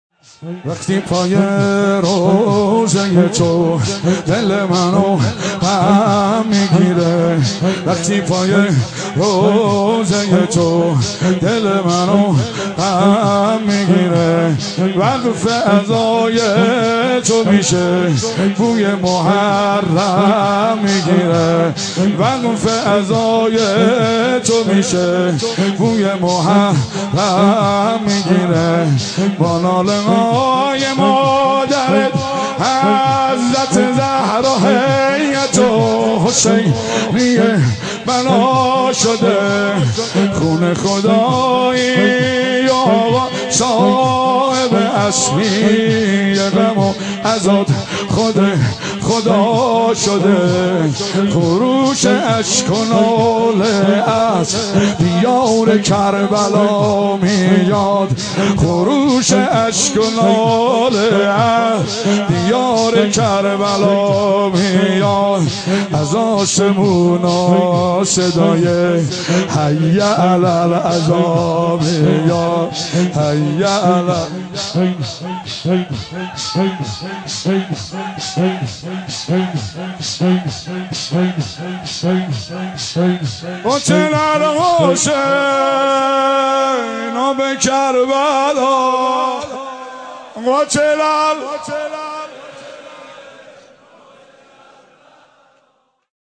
مراسم شب دهم محرم الحرام ۹۵ برگزار شد.